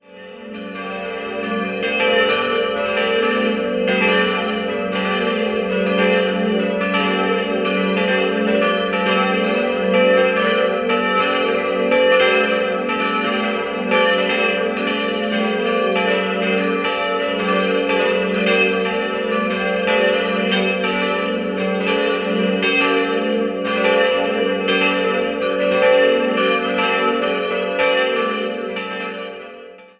Die Pfarrkirche zum Heiligen Wendelin wurde 1789 im klassizistischen Stil erbaut und besitzt eine reiche, gefällige Ausstattung. 4-stimmiges Geläut: g'-a'-h'-d'' Die Glocken wurden 1962 von der Firma Grassmayr in Innsbruck gegossen.